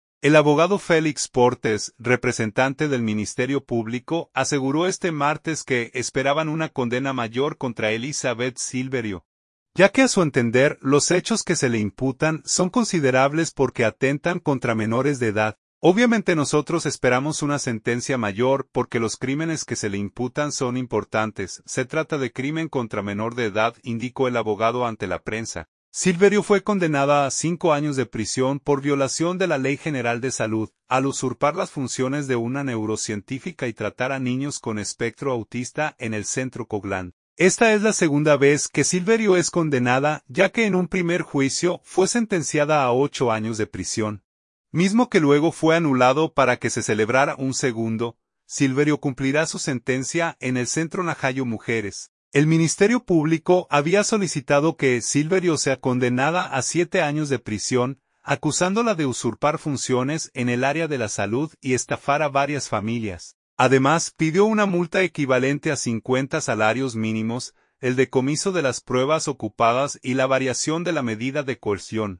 “Obviamente nosotros esperamos una sentencia mayor, porque los crímenes que se le imputan son importantes, se trata de crimen contra menor de edad”, indicó el abogado ante la prensa.